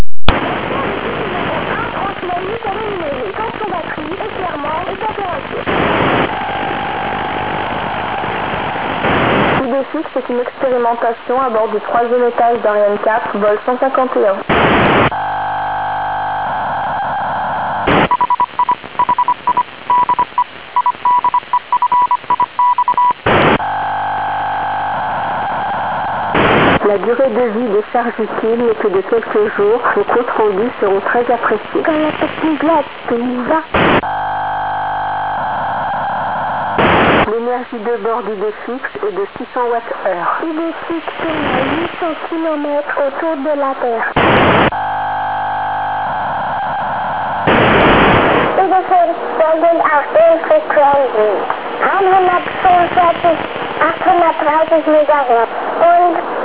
Voice messages Wav file , reception on 18-May-2002 at 09:31 UTC VHF Band  (445Kb)
report signal in VHF is S2 to S7 clear